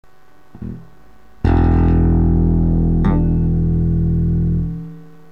雑音が耳障り、8トラック の ミキサー へ